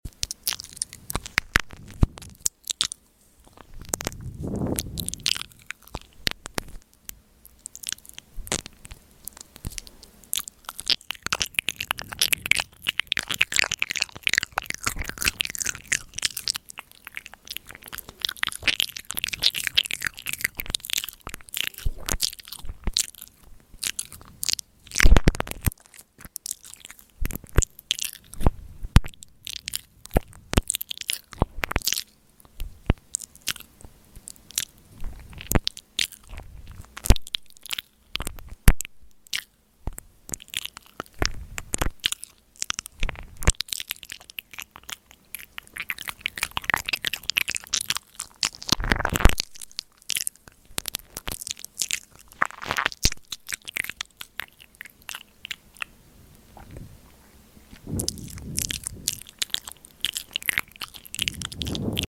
ASMR Mouth Sounds